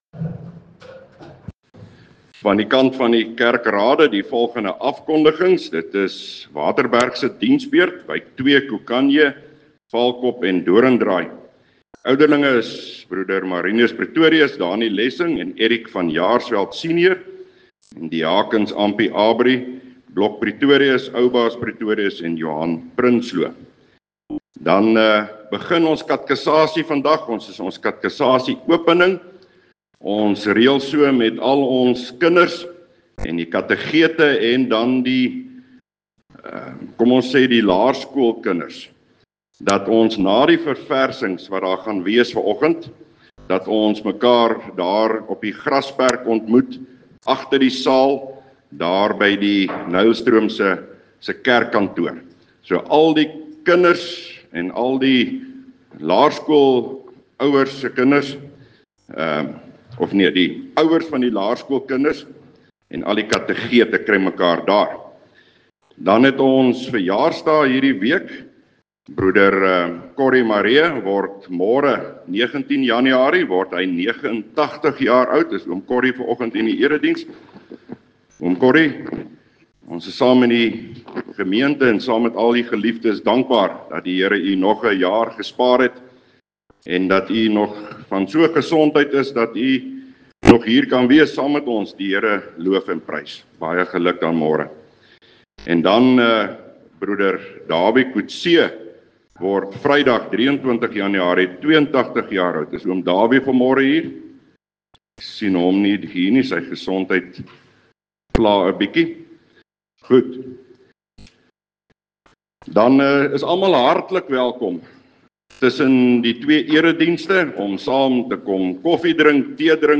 PREEK: Jesaja 8:18 – Die verbondsdoop is vir ons tot ‘n getuienis van Christus se getrouheid aan sy kerk, ook in 2026, en daarom kan ons as gesinne en kerke dankbaar gehoorsaam wees midde ‘moeilike tye’
Opname (GK Waterberg, 2026-01-18)